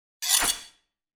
SwordSoundPack
SWORD_27.wav